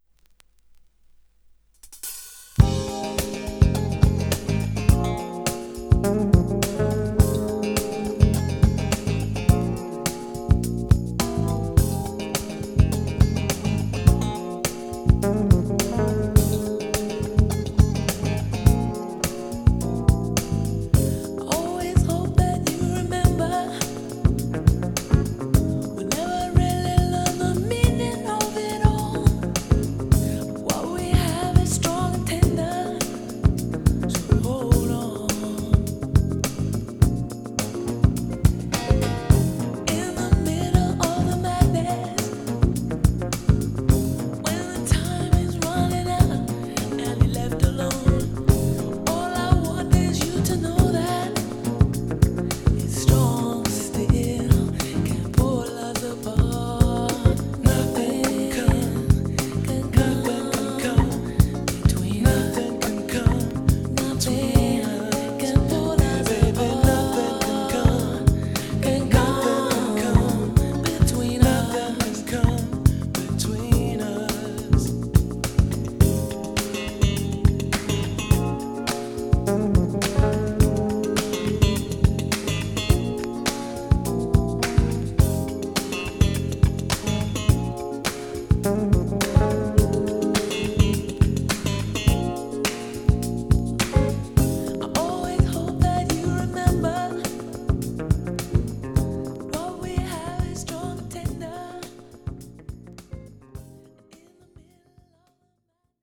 Du har förskjuten ljudbild åt vänster.
Det låter också rätt "försiktigt".